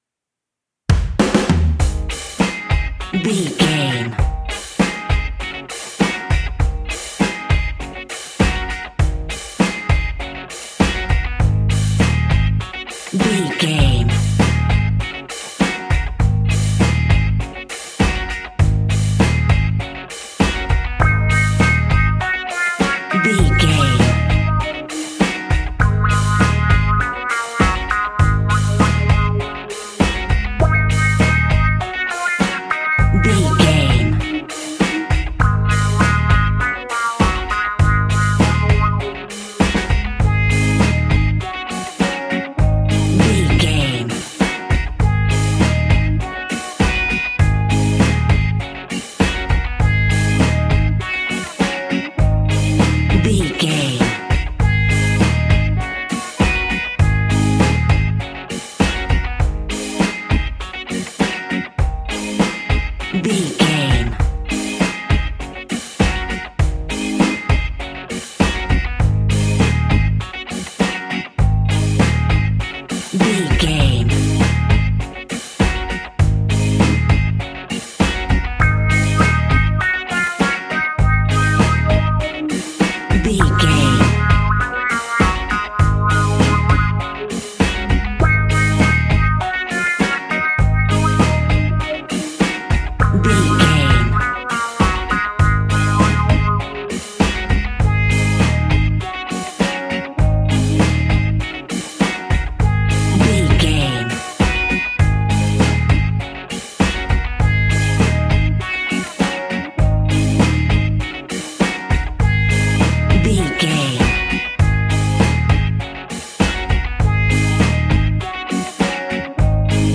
Classic reggae music with that skank bounce reggae feeling.
Uplifting
Ionian/Major
A♭
laid back
chilled
off beat
skank guitar
hammond organ
percussion
horns